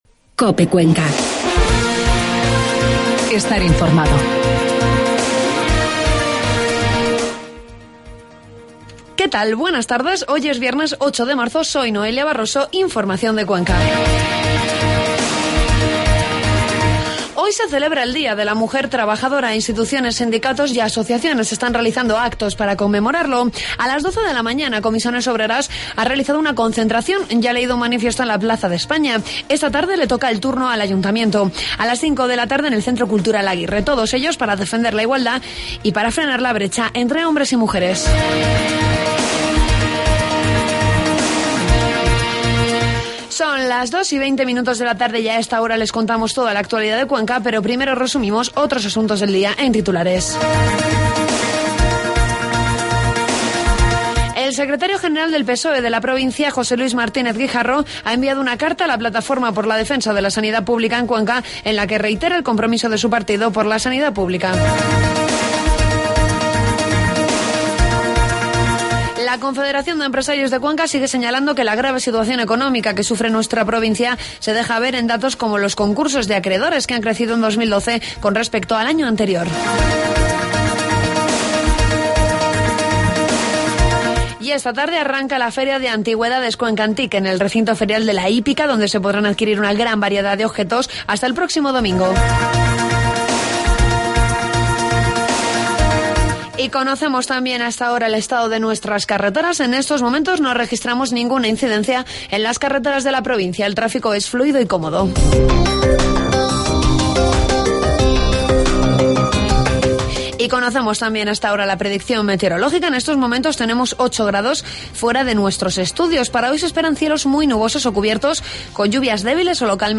Toda la información de la provincia de Cuenca en los informativos de mediodía de COPE